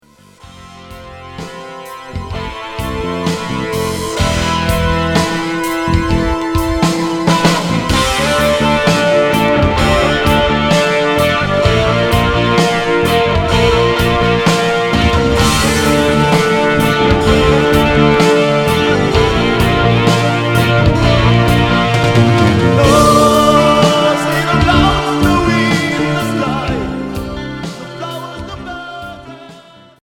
Hard progressif